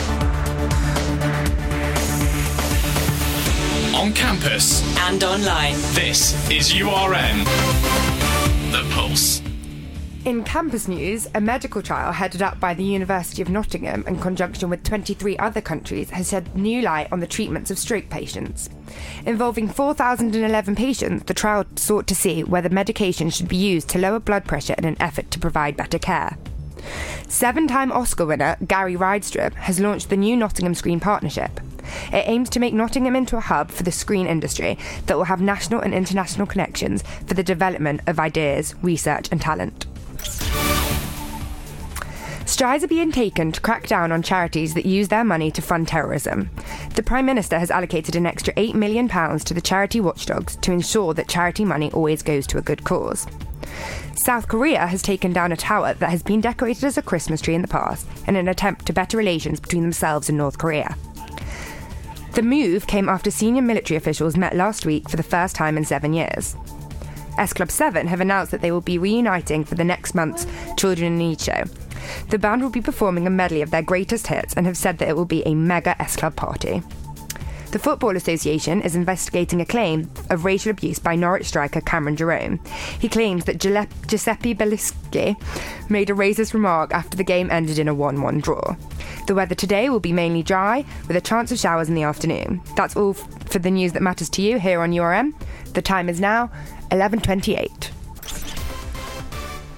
Headlines on URN 22/10/2014